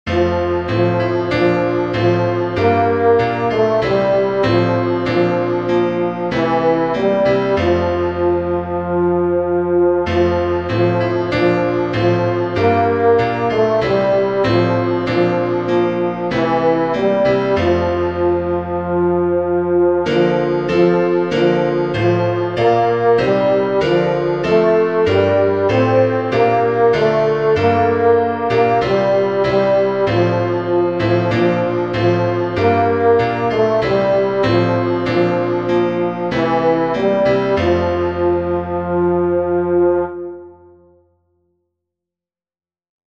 Welsh Melody
Tenor
ar_hyd_y_nos-tenor1.mp3